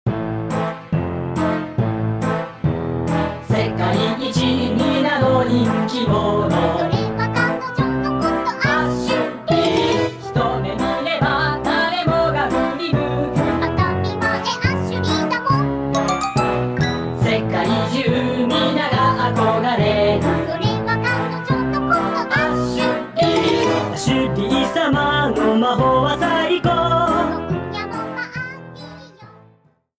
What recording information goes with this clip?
Reduced to 30 seconds, with fadeout.